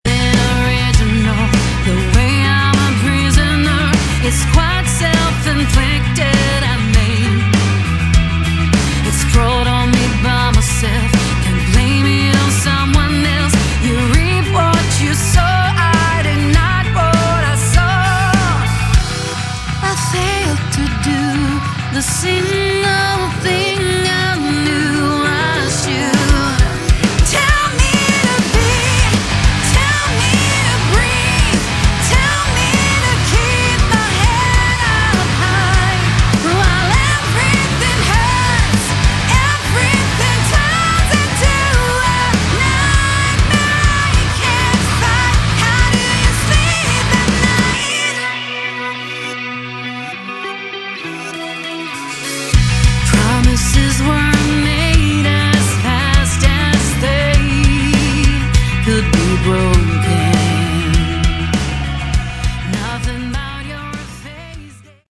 Category: Melodic Metal
vocals
guitars
bass
keyboards
drums